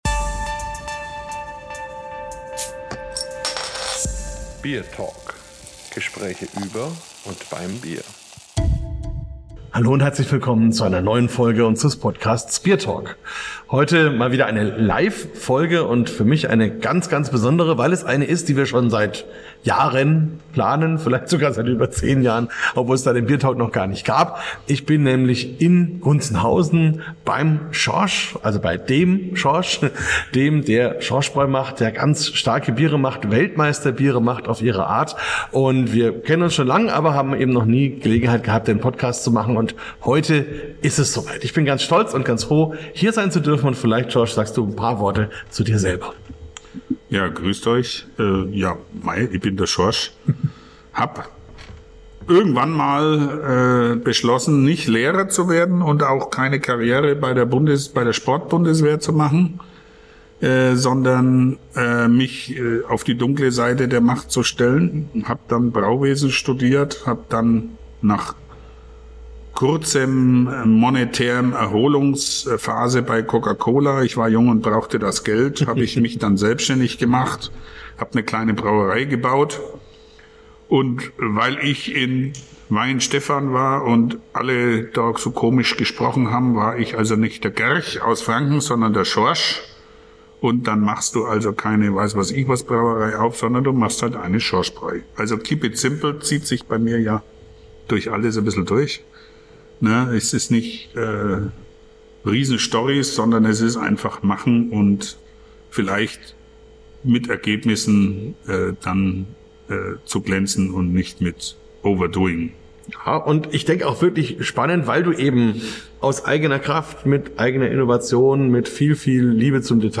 In dieser besonderen Live-Folge nehme ich euch mit hinter die Kulissen einer Erfolgsgeschichte, die mit einer Portion Sturheit, fränkischem Humor und viel Innovation begann.